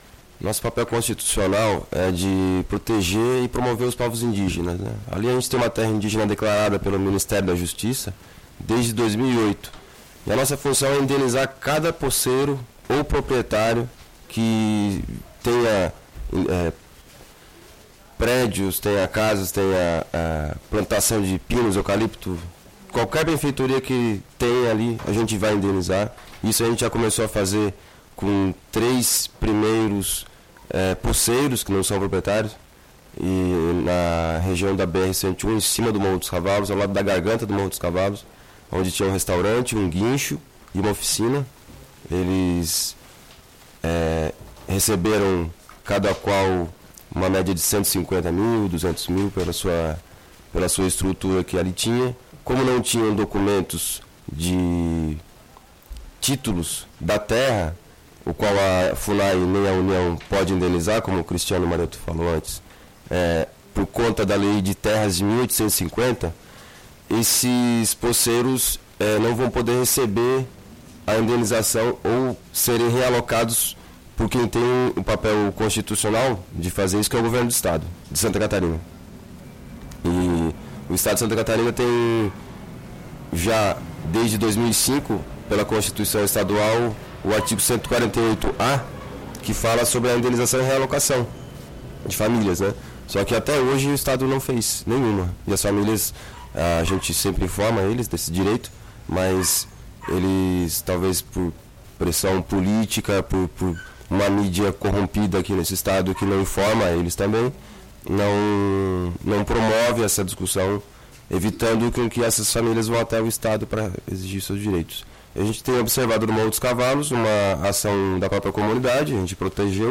Entrevistas radiofônicas